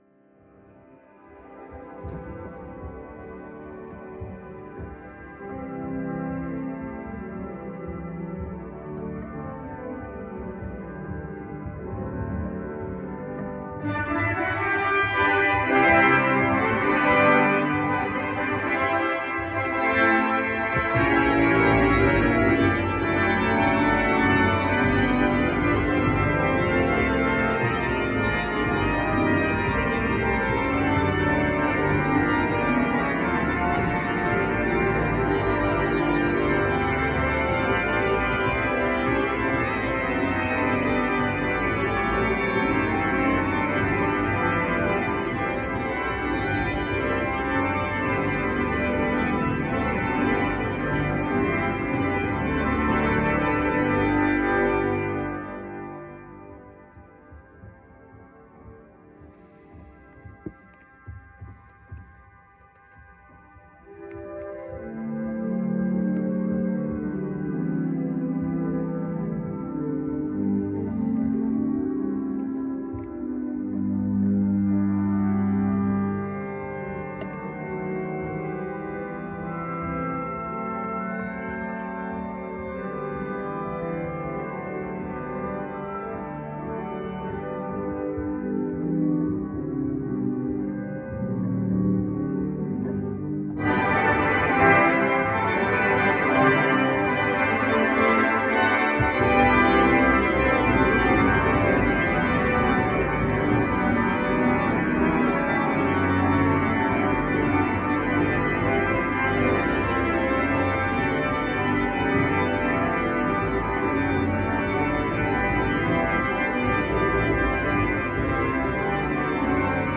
Eine kleine Orgelimproviation auf der Eule-Orgel (2018) der Abteikirche, auf der ich einmal kurz Gelegenheit zum Spielen hatte, gibt es hier --->
2024---improvisation-abteikirche-brauweiler.m4a